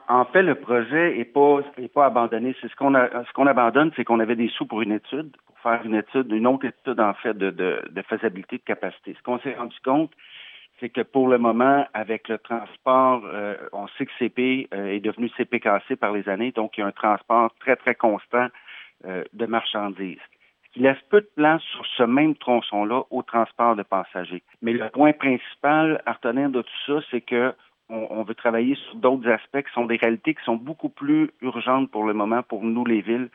En entrevue avec le service de nouvelles de M105, le maire de Farnham et président de l’ACFEM Patrick a toutefois tenu à remettre les pendules à l’heure. Il précise qu’il s’agirait plutôt de la fin de l’étude de faisabilité du projet, tandis que le projet lui-même serait mis sur pause.